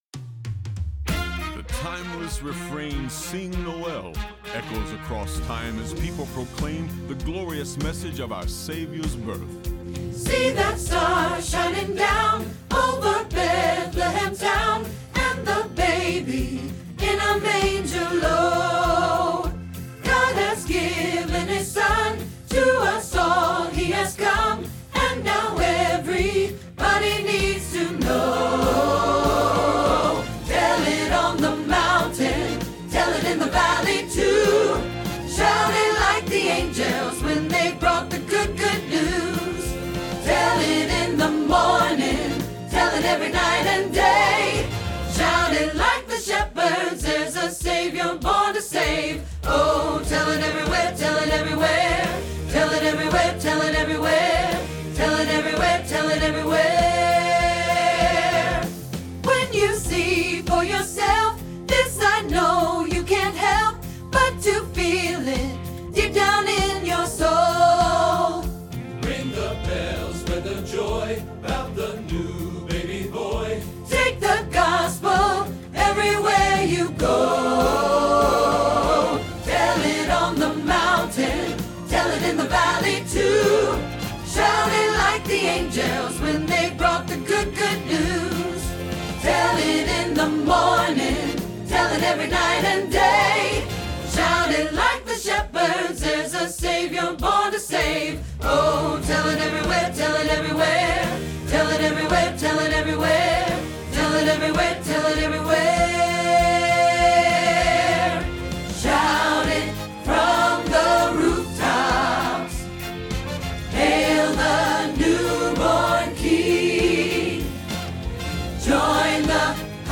Alto Practice Trax